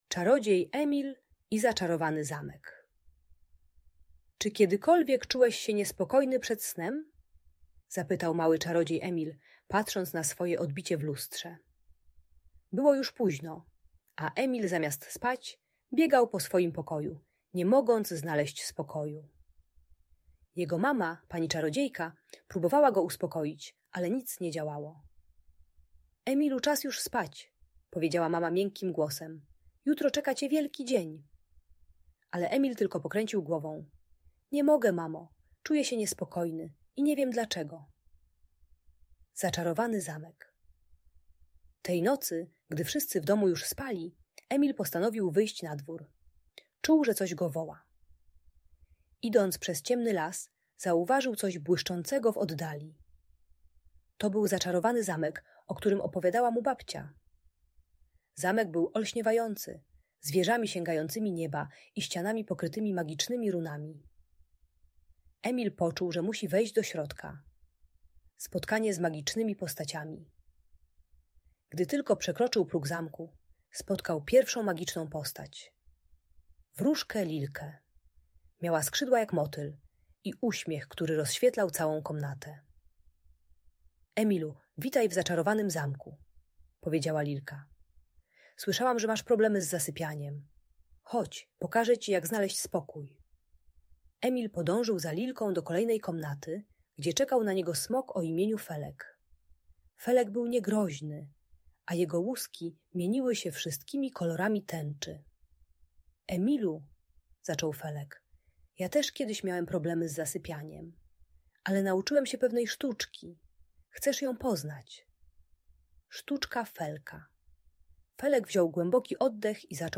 Opowieść o czarodzieju Emilu i zaczarowanym zamku - Audiobajka